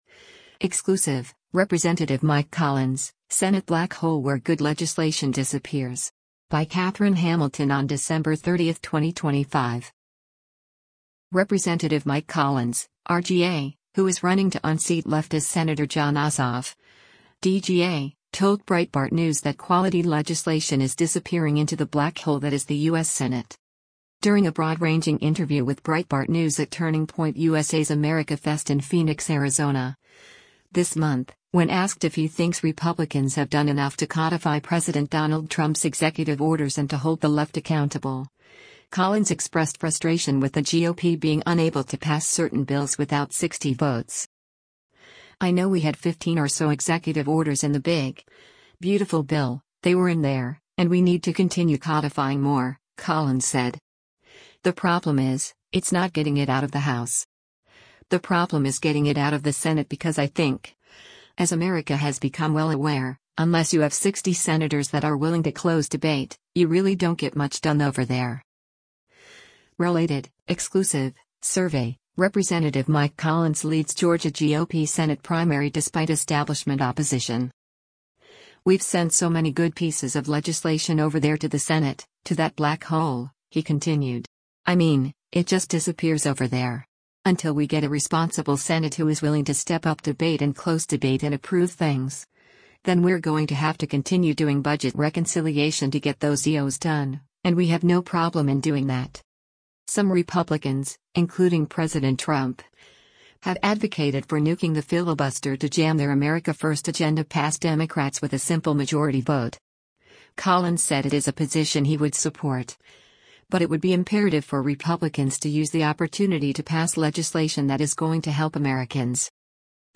During a broad-ranging interview with Breitbart News at Turning Point USA’s AmericaFest in Phoenix, Arizona, this month, when asked if he thinks Republicans have done enough to codify President Donald Trump’s executive orders and to hold the left accountable, Collins expressed frustration with the GOP being unable to pass certain bills without 60 votes.